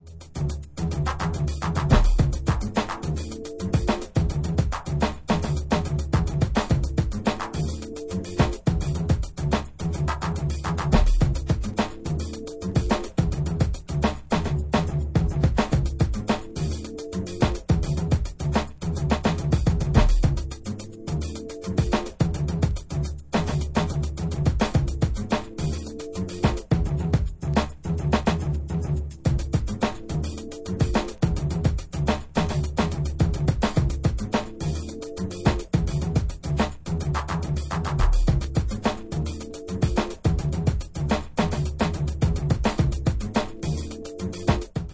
var. style deep minimal electro techno tracks